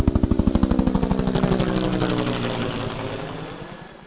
HELI.mp3